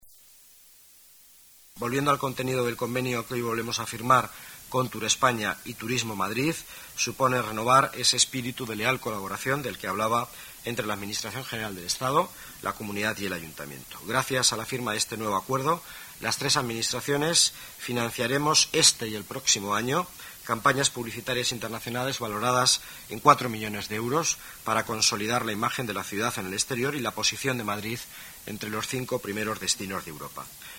Nueva ventana:Declaraciones del delegado de Economía, Miguel Ángel Villanueva: Convenio Turespaña